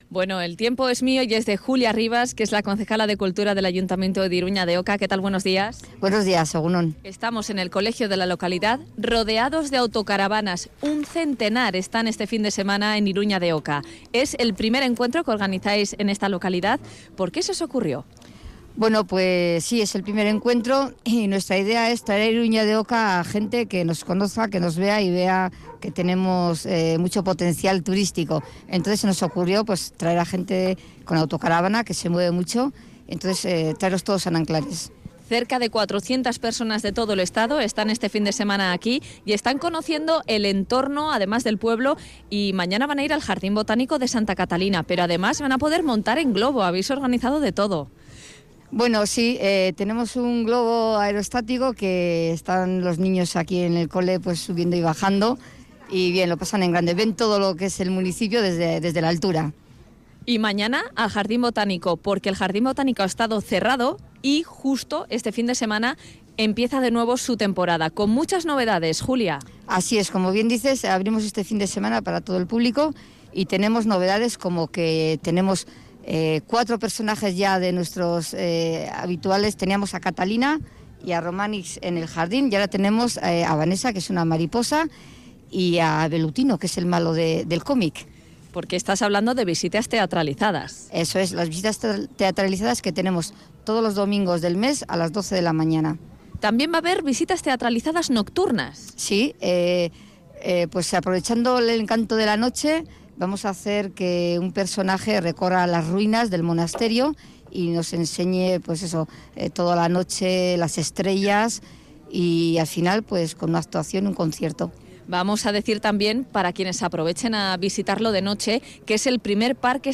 entrevista a Julia Rivas, concejala de cultura de Iruña de Oca. Con ella habla de la nueva temporada del Jardín Botánico de Santa Catalina y del Primer Encuentro de Autocaravanas.